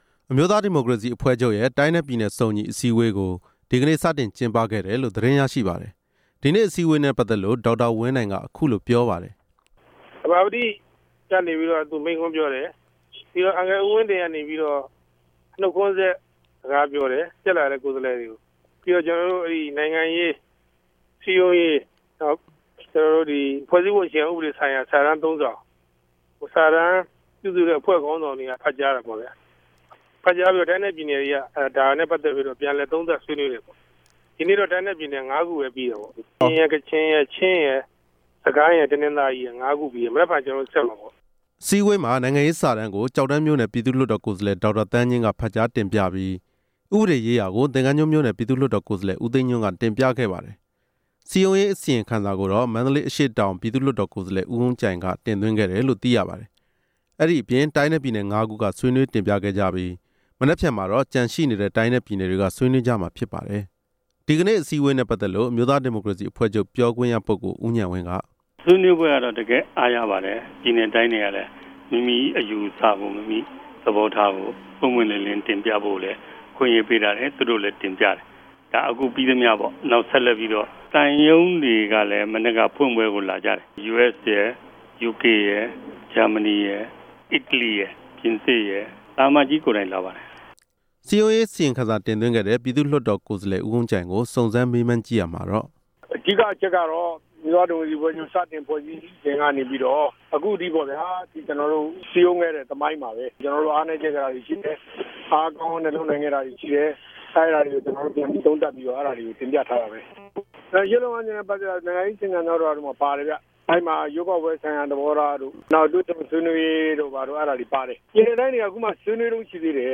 သတင်းတင်ူပခဵက်။